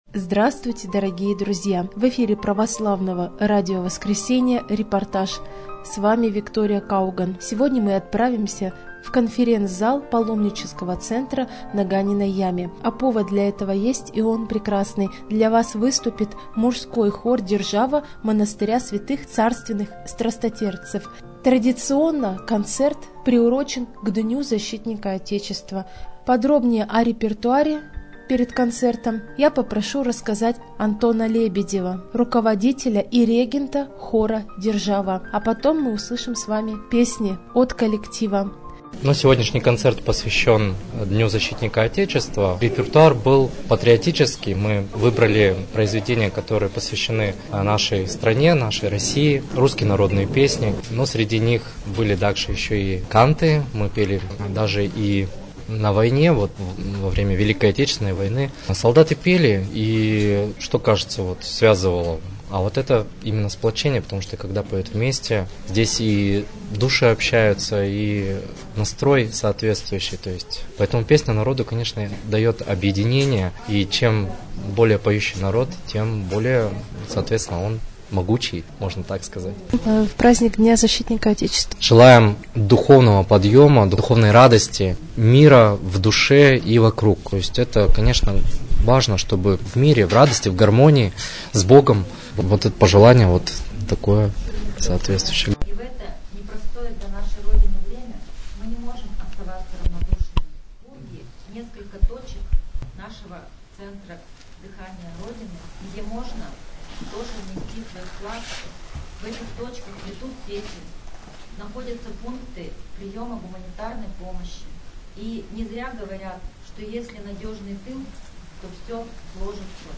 Концерт, посвящённый Дню Защитника Отечества.
koncert_posvyashchyonnyj_dnyu_zashchitnika_otechestva.mp3